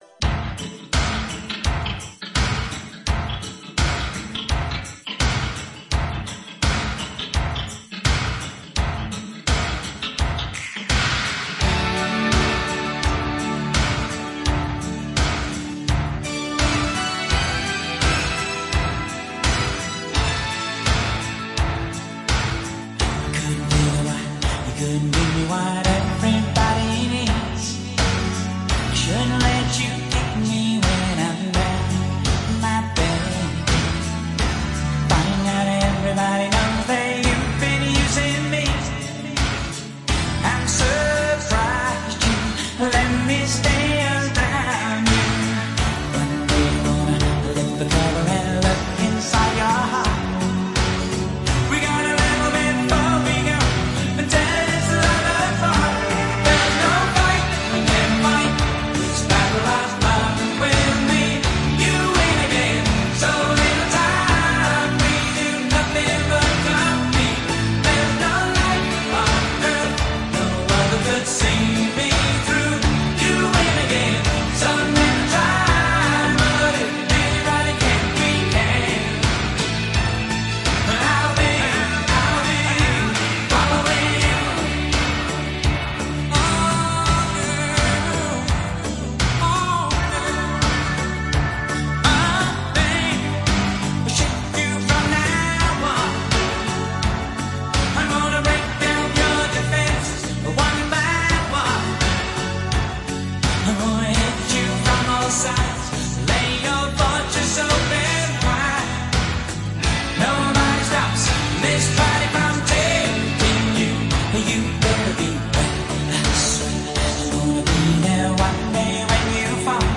Там был только один барабан.